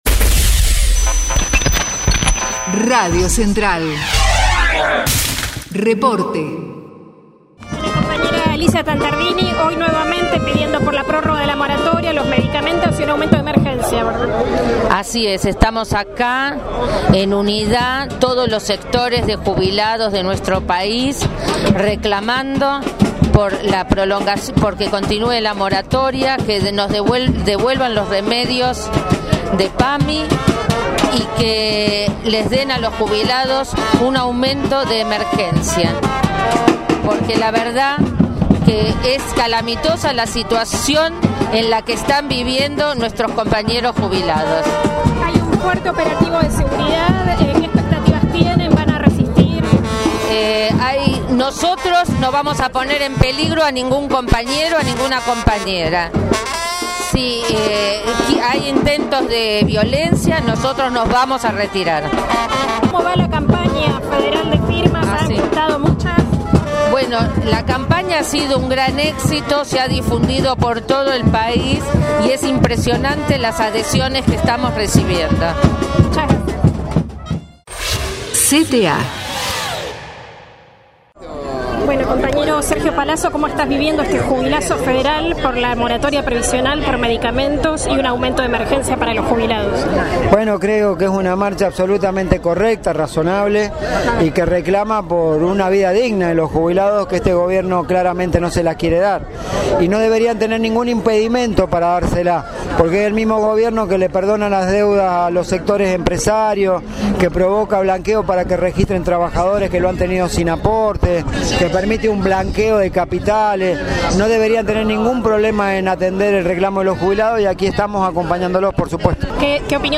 JUBILAZO FEDERAL - Testimonios en Plaza Congreso